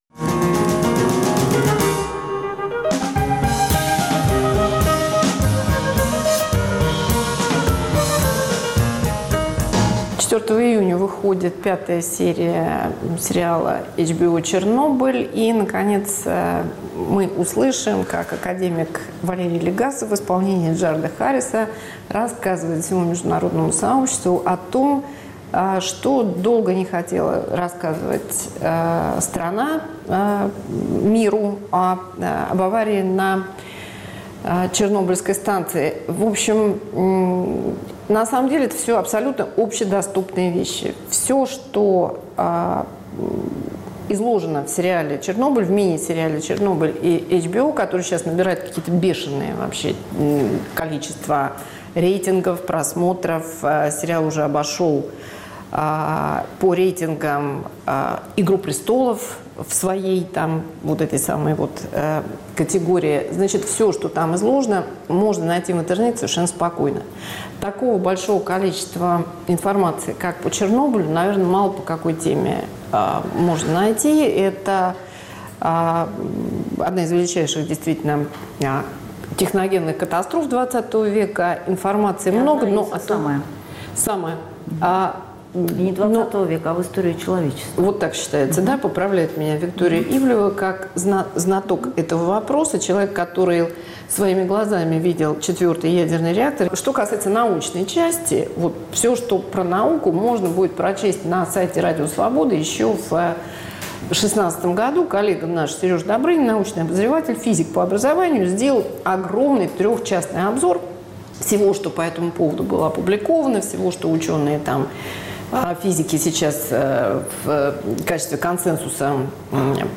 Разговор о мини-сериале "Чернобыль" HBO в историческом и социальном контексте распада Союза и современной российской реальности